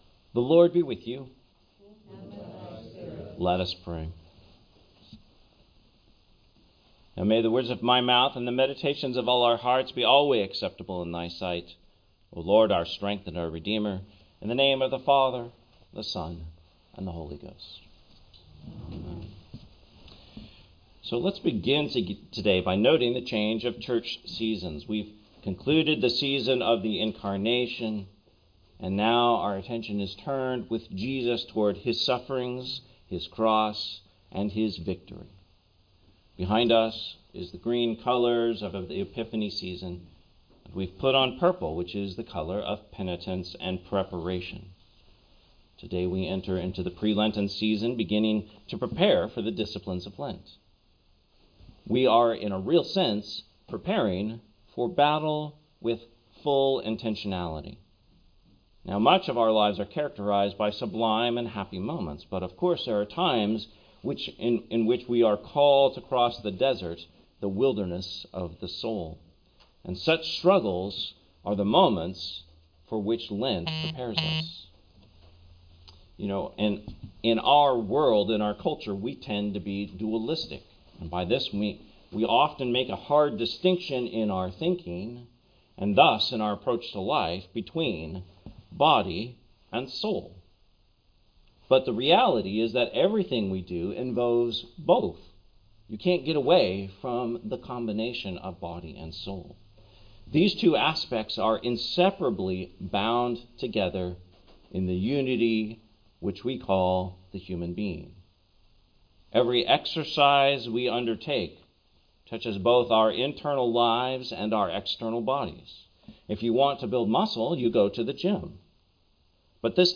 Sermon, Septuagesima Sunday, 2025